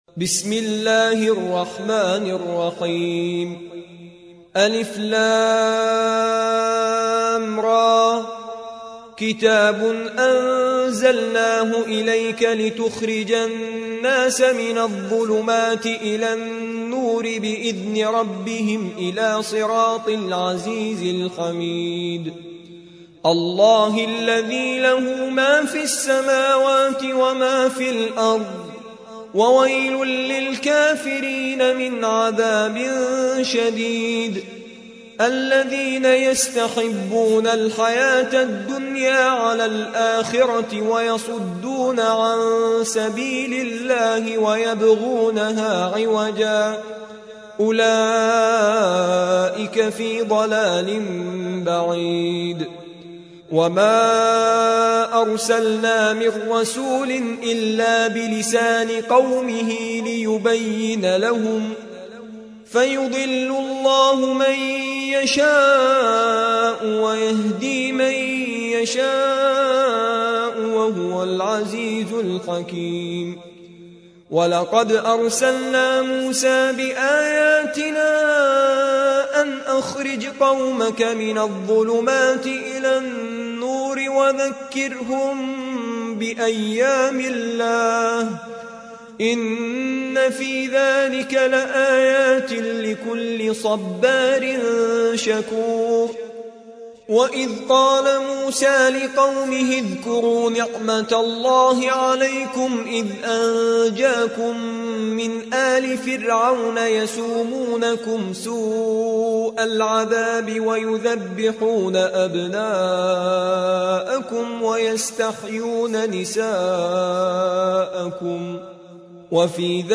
14. سورة إبراهيم / القارئ